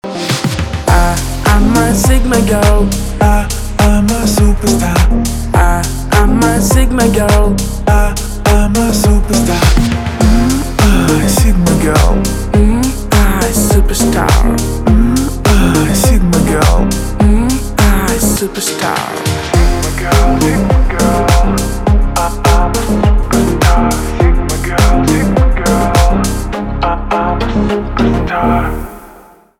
поп
басы